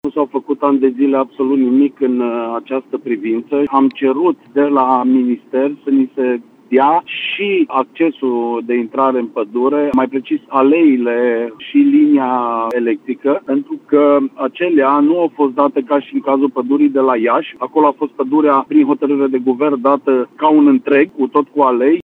Pentru aceasta însă, are nevoie ca inclusiv aleile din pădure să îi fie transferate în proprietate, lucru care nici până astăzi nu s-a întâmplat, spune viceprimarul Cosmin Tabără.